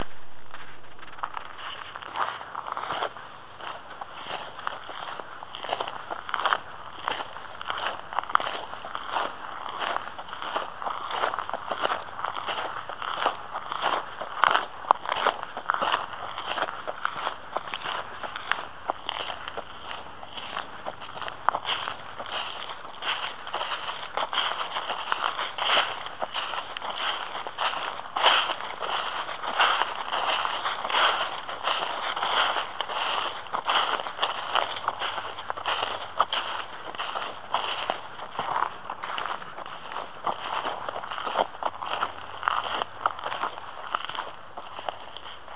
Come hike with me on the blue trail at Bullfrog Lake!
Click to listen (hiking on gravel trail with dried leaves)
If you cannot hike on your own for health reasons or such, just turn up your volume, listen to the sound of hiking on the trail, and look at the trail photos.
hiking_gravel_and_dried_leaves.mp3